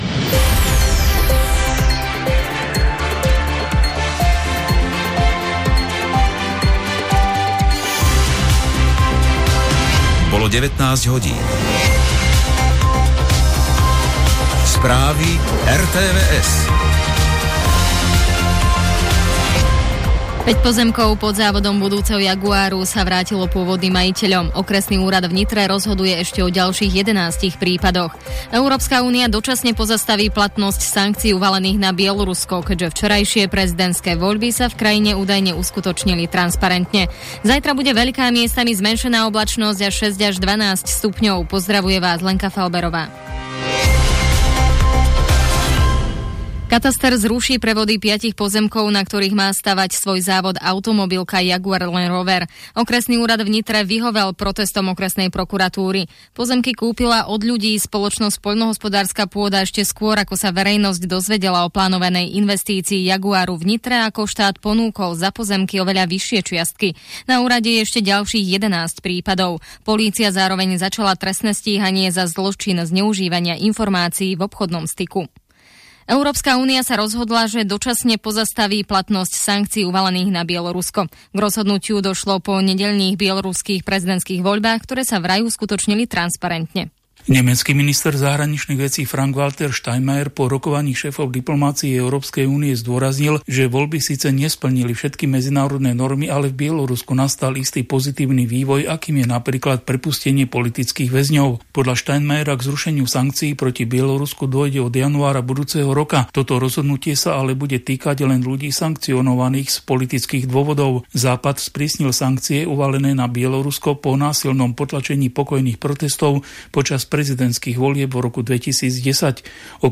správach Rádia Slovensko.